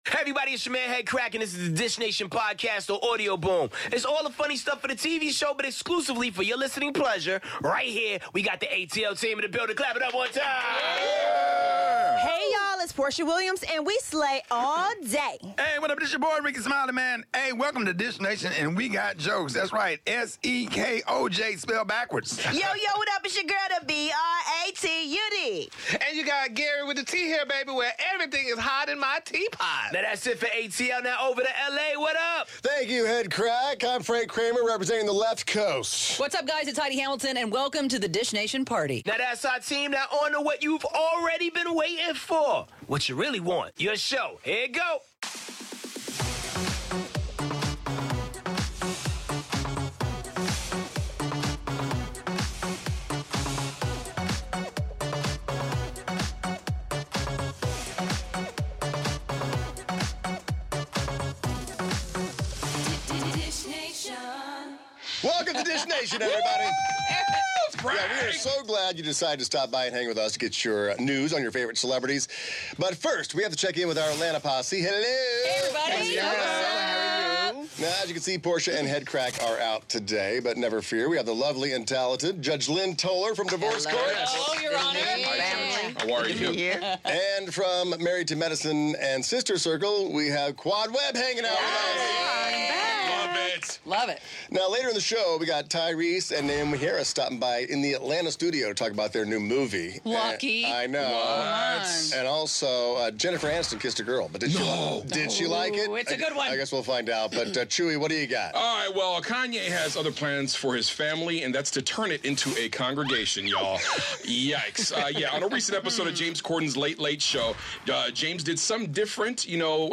Jennifer Aniston kissed a girl and she liked it, Ben Affleck has a new boo and did Chrissy Teigen research to see if John Legend was in the closet? We've got Quad Webb-Lunceford and Divorce Court's Judge Lynn in studio so be sure to tune in for the tea!